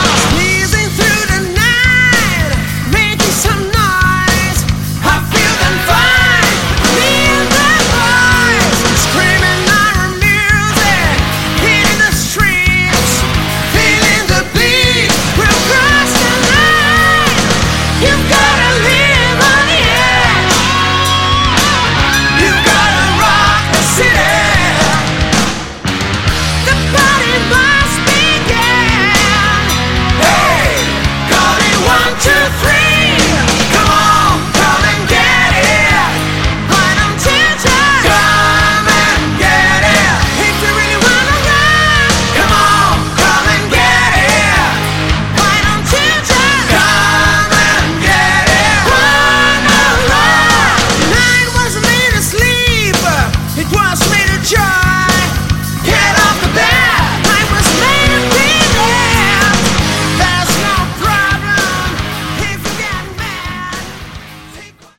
Category: Melodic Hard Rock
guitar, vocals
bass
keyboards
drums